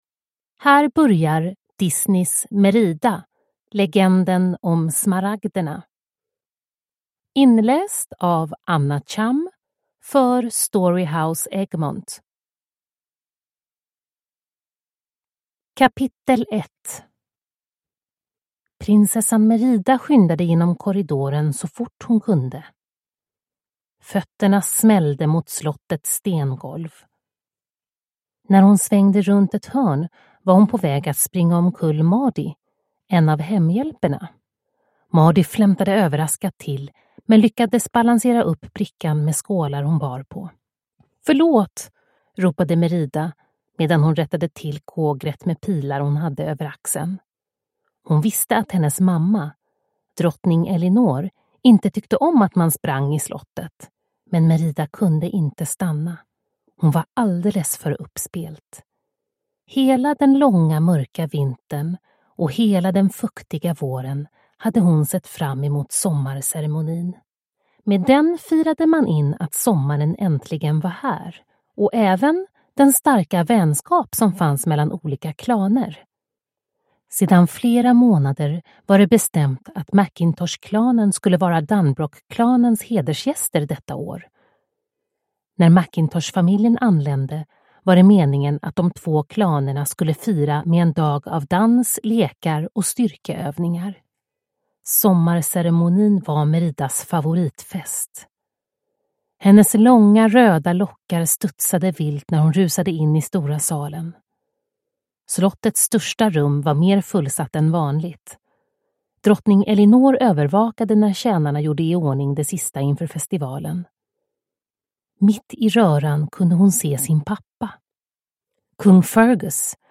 Merida. Legenden om smaragderna – Ljudbok